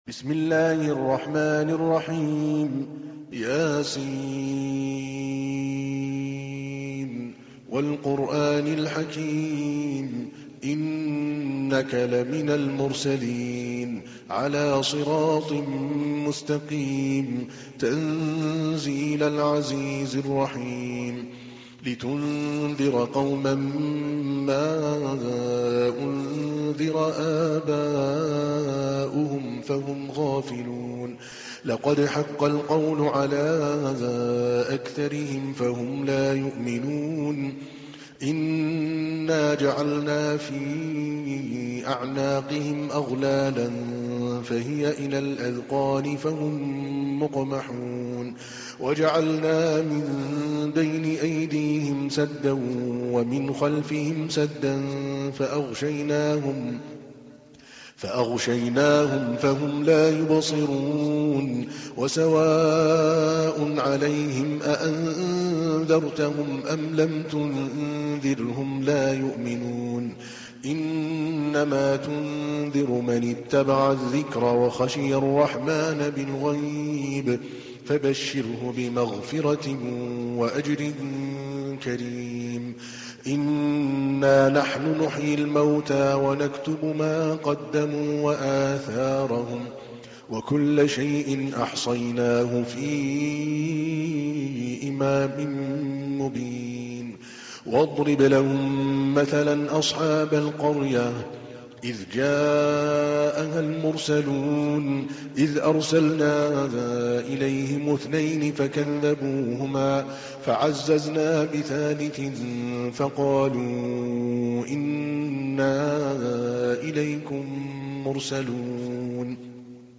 تحميل : 36. سورة يس / القارئ عادل الكلباني / القرآن الكريم / موقع يا حسين